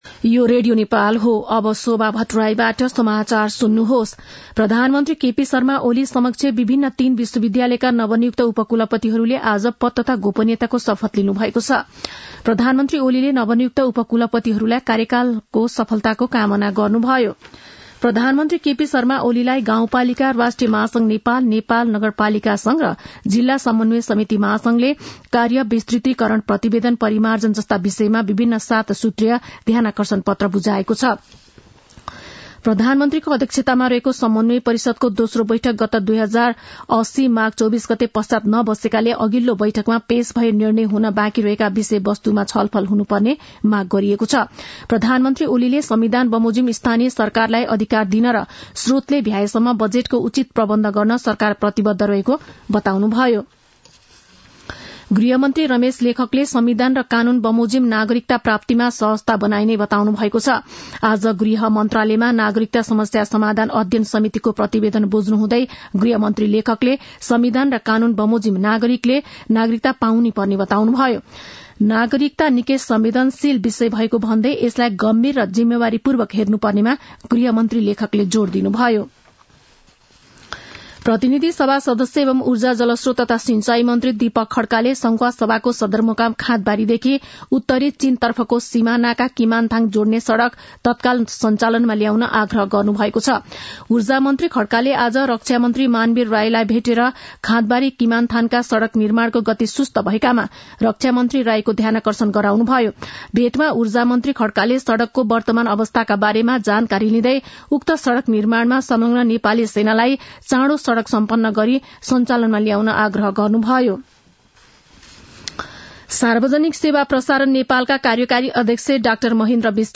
साँझ ५ बजेको नेपाली समाचार : १३ पुष , २०८१